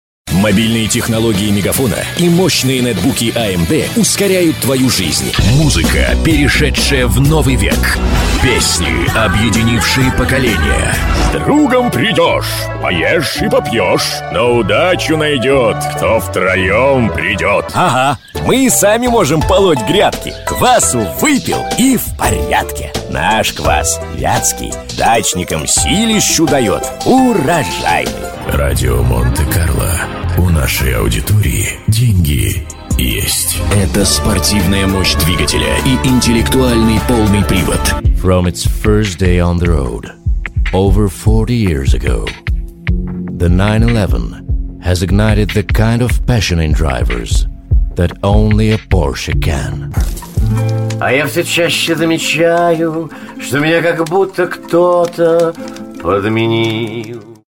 Представительный баритон бродвейского актера, универсальный диктор с огромным рекламным опытом.